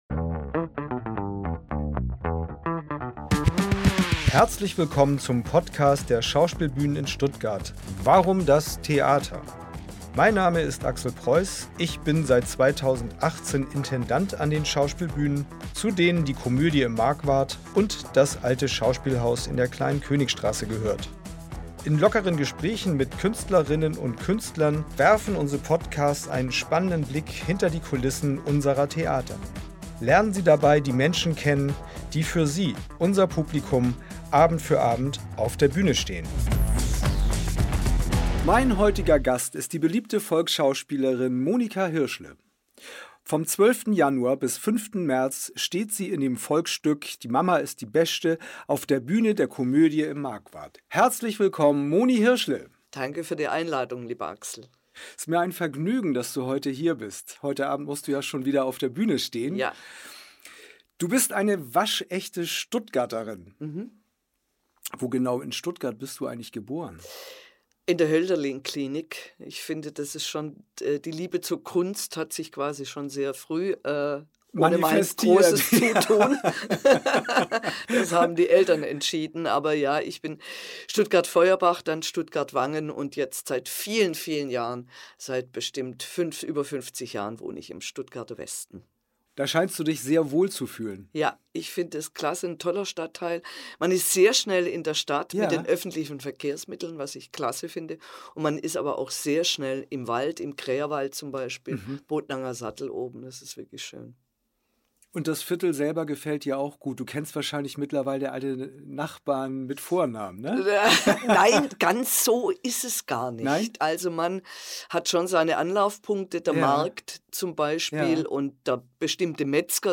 Unser Schauspielbühnen-Talk präsentiert Ihnen regelmäßig Gespräche mit unseren Künstlerinnen und Künstlern. Lernen Sie die Menschen hinter den Bühnencharakteren kennen und erfahren Sie, wie Theater hinter den Kulissen funktioniert.